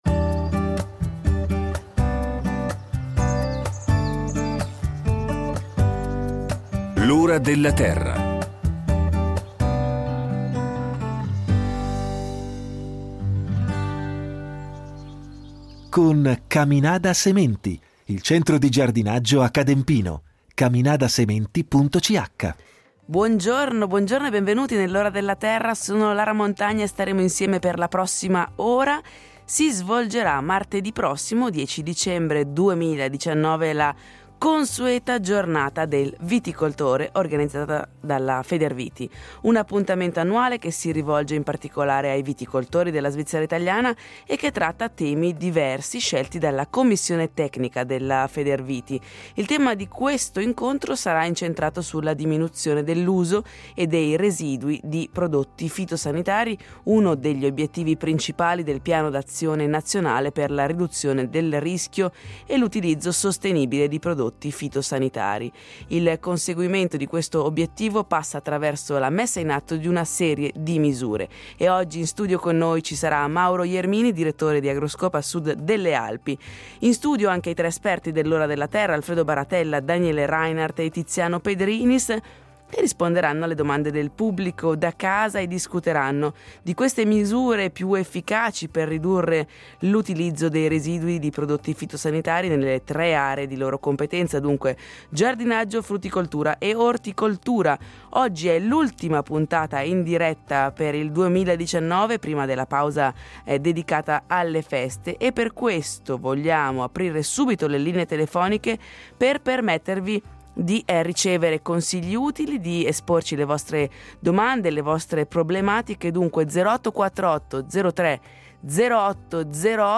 Questa sarà l’ultima puntata in diretta prima della pausa natalizia.